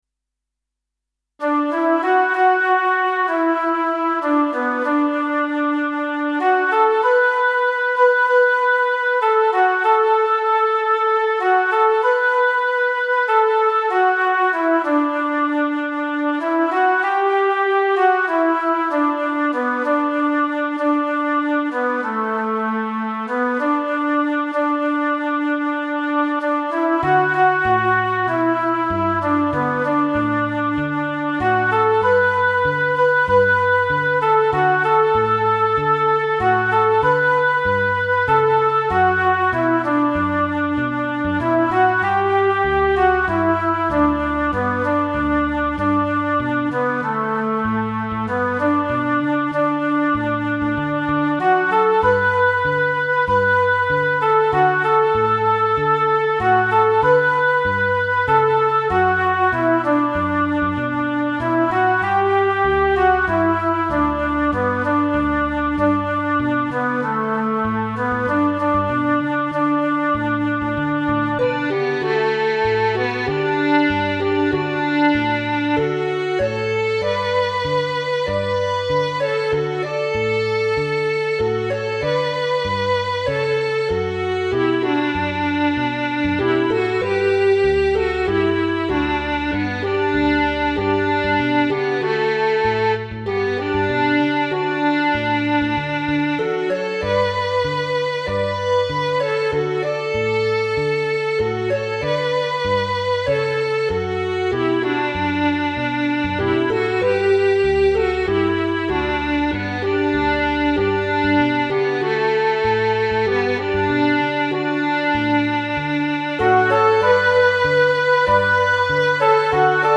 Home > Music > Pop > Bright > Folk > Medium